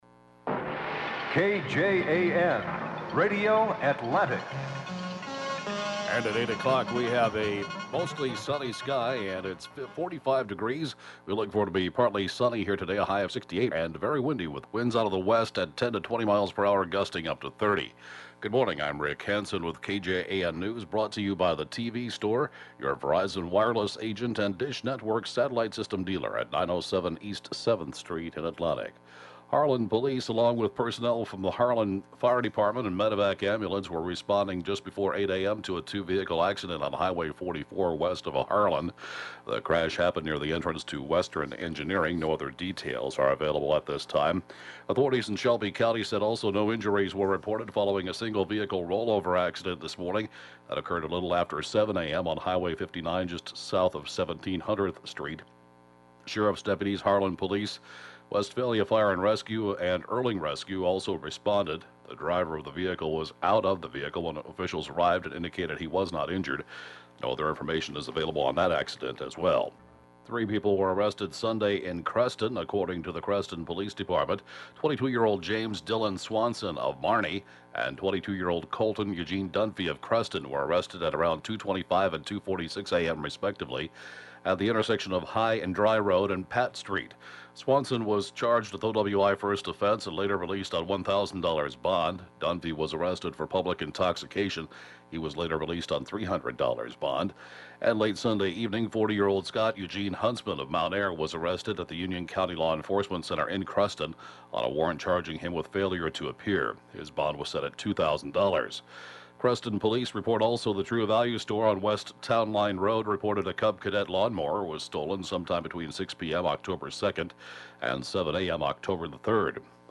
(Podcast) 8-a.m. Area/State News: Mon. 10/6/2014